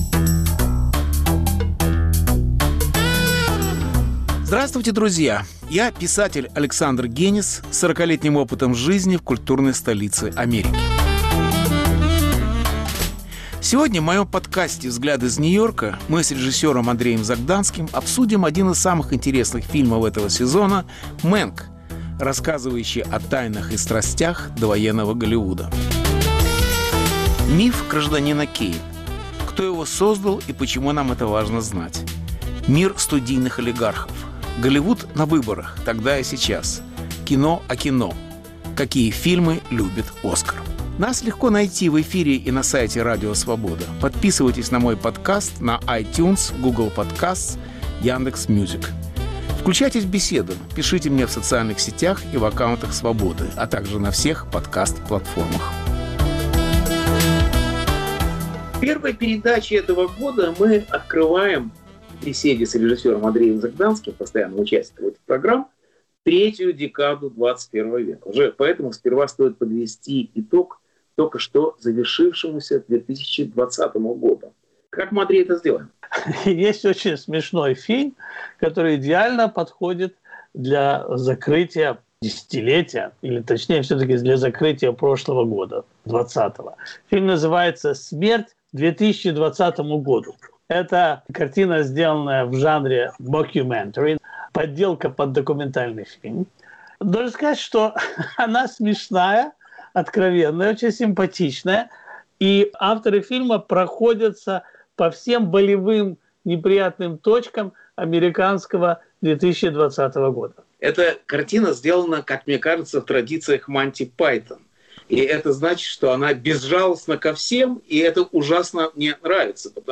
Беседа о кино